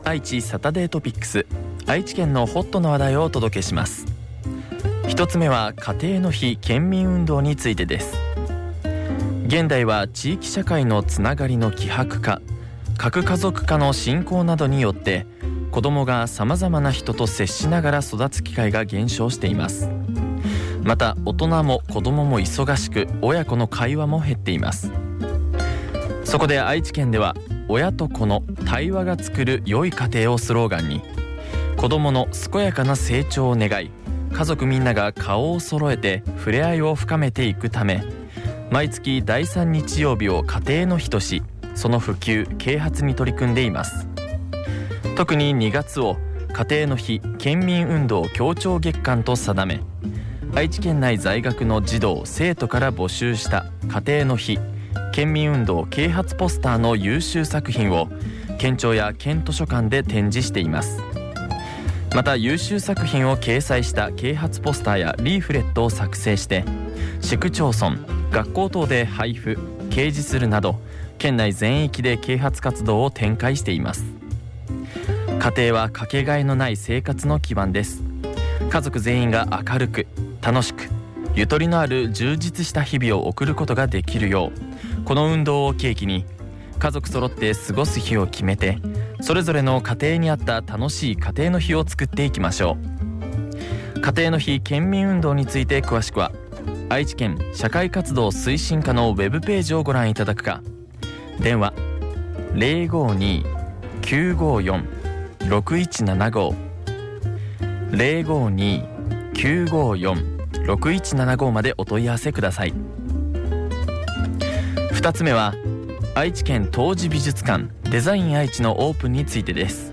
広報ラジオ番組